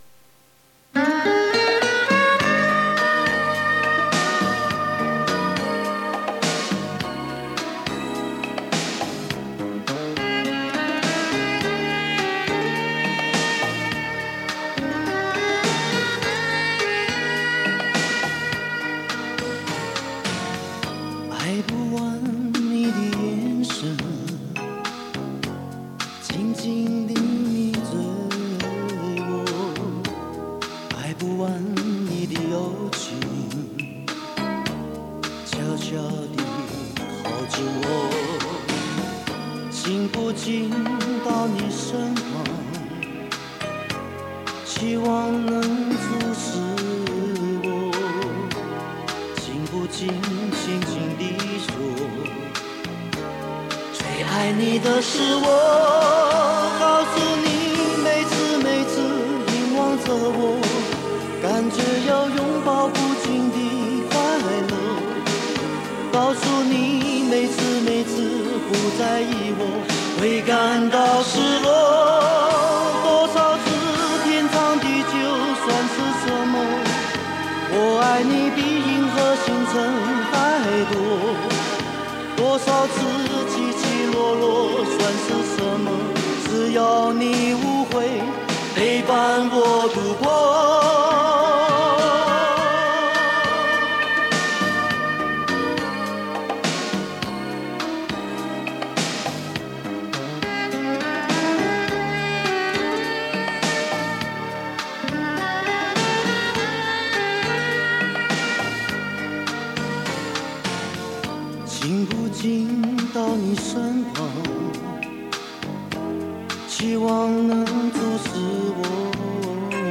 磁带数字化：2022-07-02